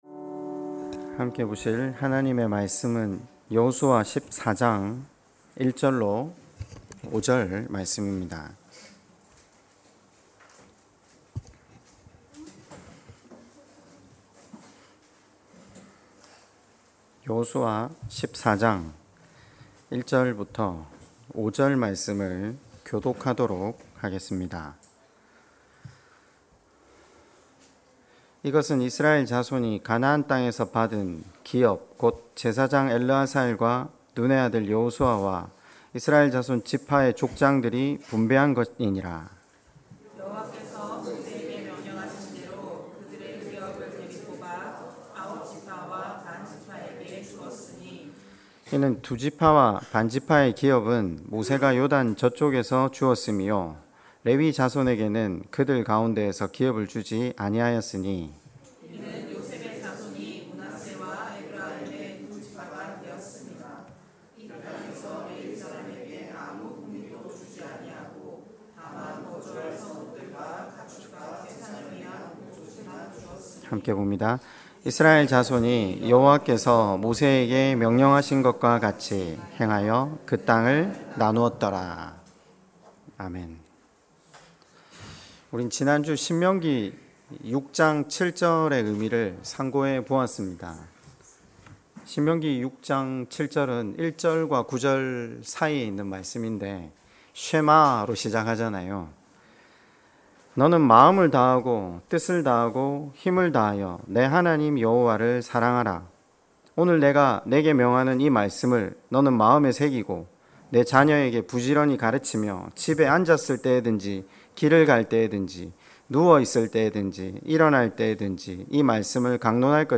설교 - 2023년 09월03일 사무엘상 16장 13-18절 (먹고 마시는 문제보다 읽고 듣고 보는 것이 더 중요한 이유)